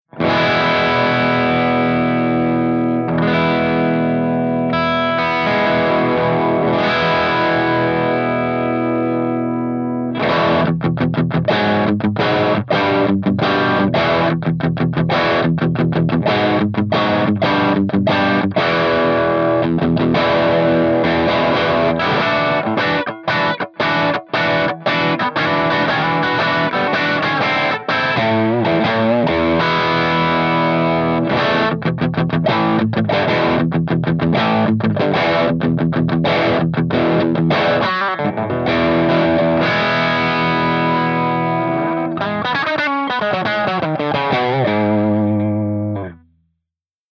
137_ROCKERVERB_CH2CRUNCH_V30_P90